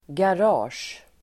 Ladda ner uttalet
garage substantiv, garage Uttal: [gar'a:sj] Böjningar: garaget, garage, garagen Definition: byggnad eller lokal för bilparkering (premises for the parking of a car or cars) Sammansättningar: garage|plats (parking place)